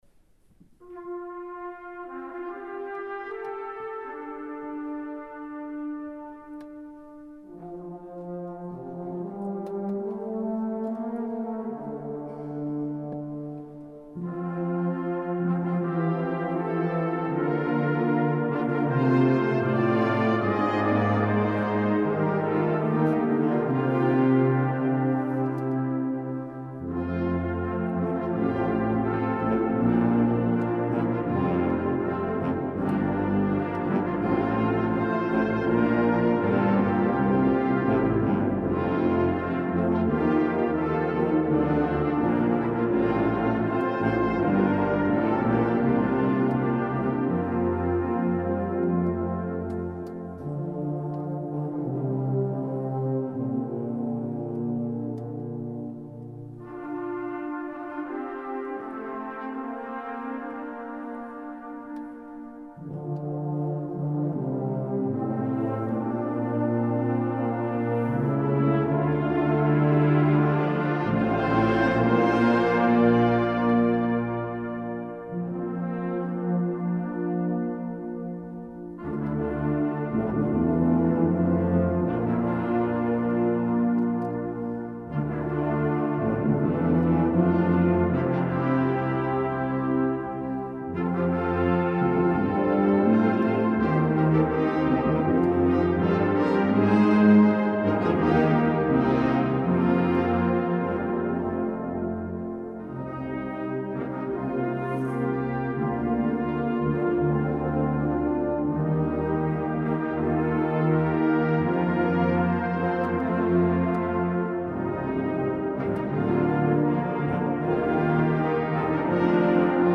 Bläsermusik proben
Die Aufnahmen wurden von verschiedenen Ensembles aufgenommen und zur Verfügung gestellt. Nicht mit dem Anspruch einer perfekten CD-Aufnahme, sondern als Hilfe für Chorleiterinnen und Chorleiter oder einzelne Mitspieler, um sich einen Klangeindruck der Stücke zu verschaffen.
Jens Uhlenhoff, Ensemble der Posaunenwarte, Gloria 2024 S. 14